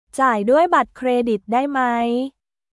ジャイ ドゥアイ バットクレジット ダイ マイ？